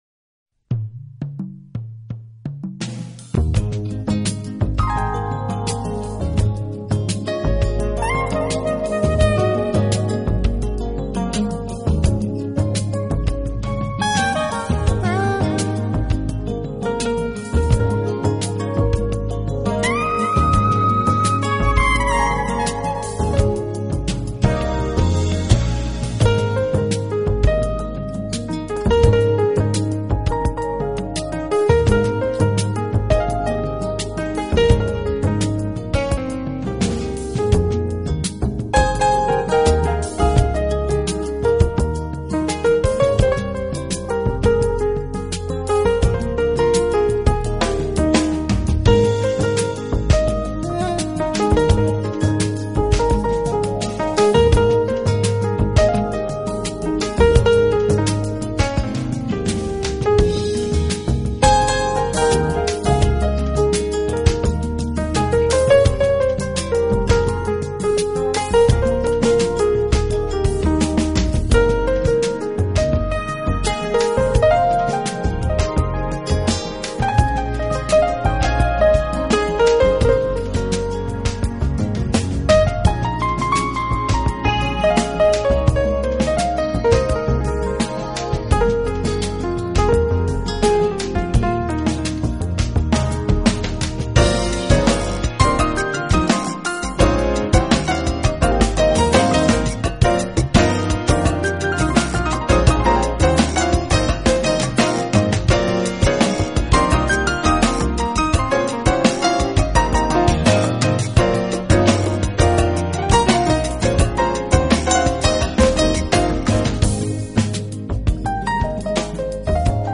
时也被称为Smooth Jazz(柔顺爵士)。